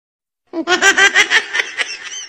Risa de bebe meme sound effects free download